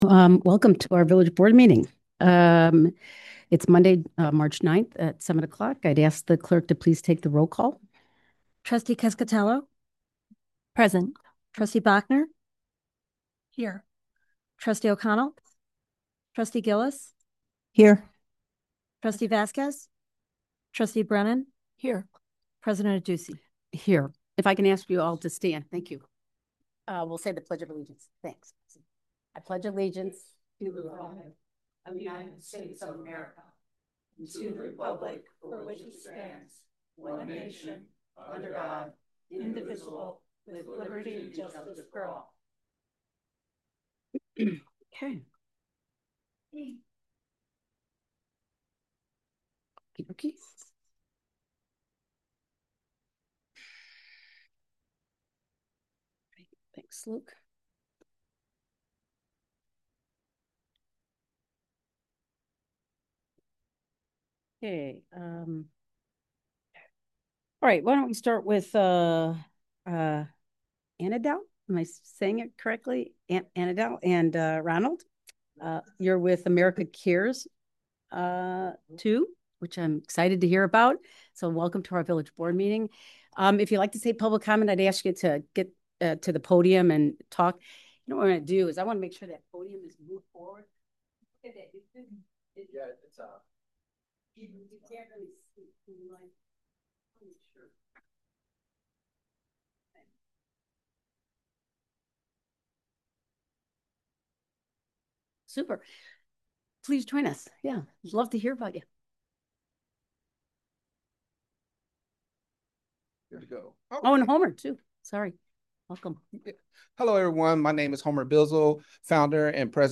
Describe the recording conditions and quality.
Village Hall - 1st Floor - COMMUNITY ROOM - 400 Park Avenue - River Forest - IL